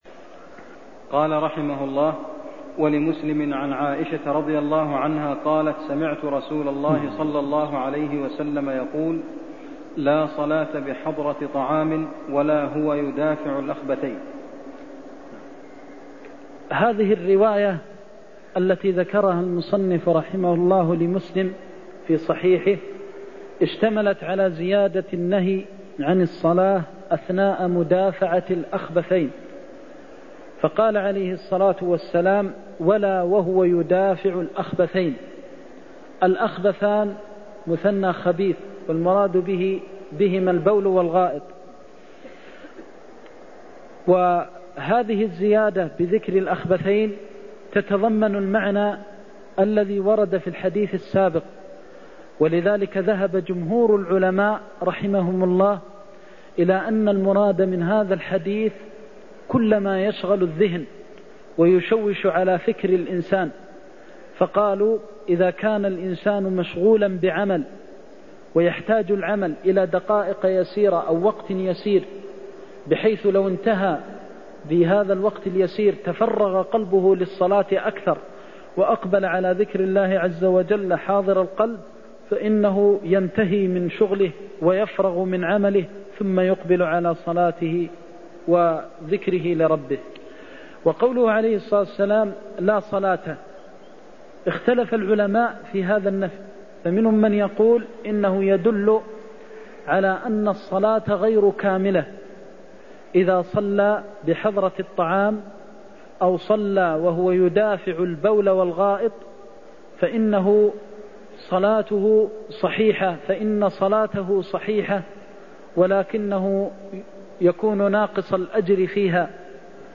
المكان: المسجد النبوي الشيخ: فضيلة الشيخ د. محمد بن محمد المختار فضيلة الشيخ د. محمد بن محمد المختار لا صلاة بحضرة طعام (51)